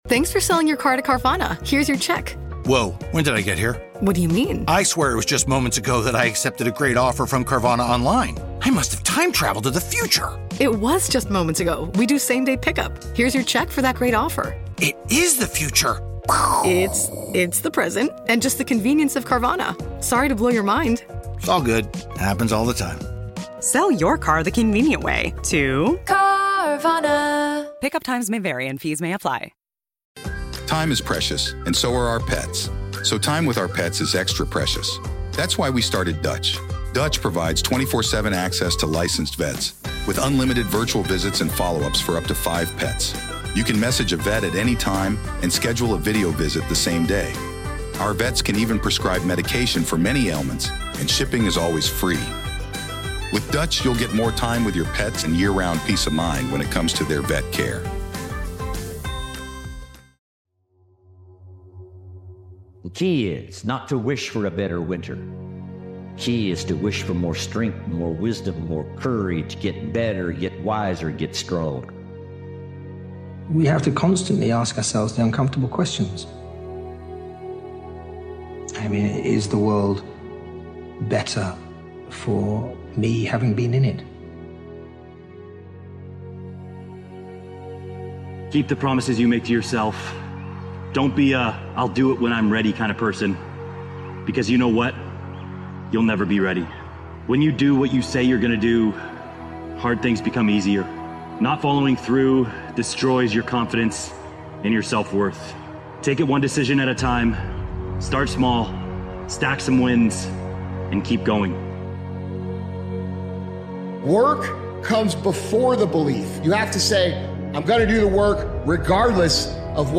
We feature the best speakers worldwide and our original motivational speakers.